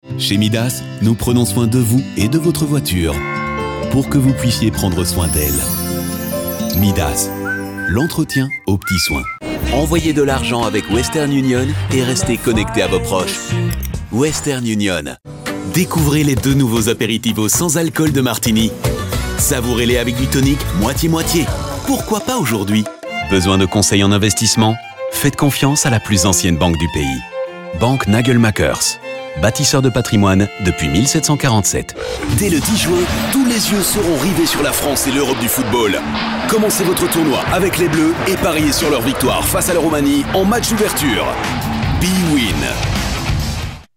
Demo compilation -....mp3